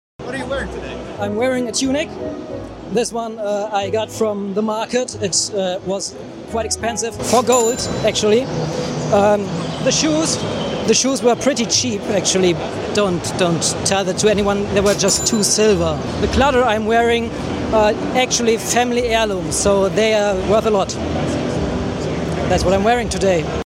Yes, there are real romans at out Anno 117 booth on gamescom